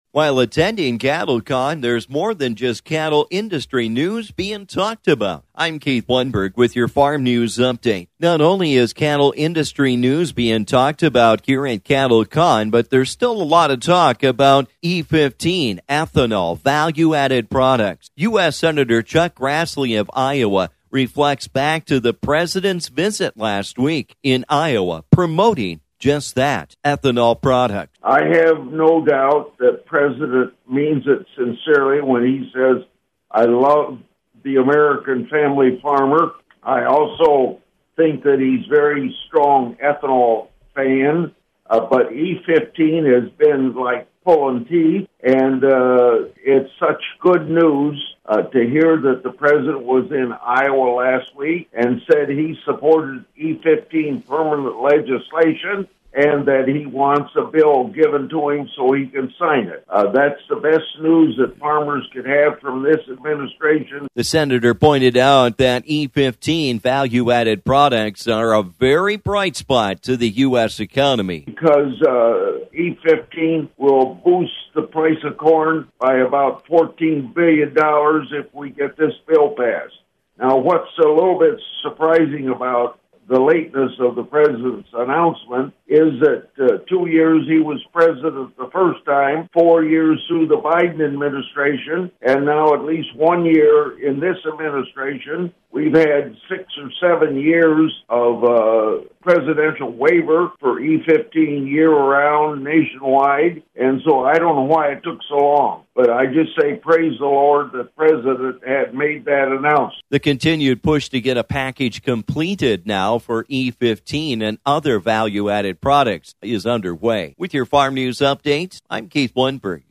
While attending Cattle Con 26, not only are we talking about the cattle Industry, but we are also talking about the importance of our value-added products, like E15.